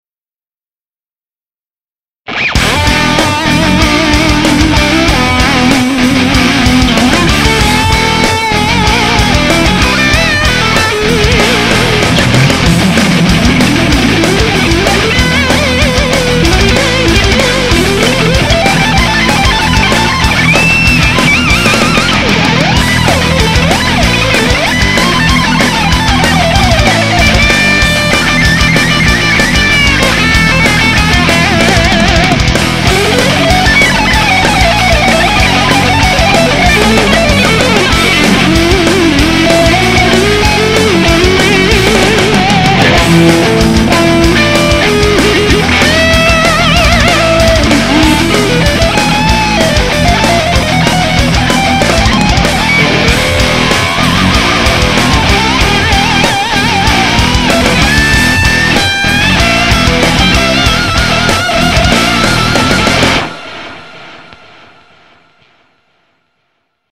Forum Jam Track - 190bpm E Standard